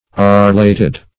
Search Result for " arllated" : The Collaborative International Dictionary of English v.0.48: Arillate \Ar"il*late\ Arllated \Ar"l*la`ted\, Ariled \Ar"iled\, a. [Cf. NL. arillatus, F. arill['e].] Having an aril.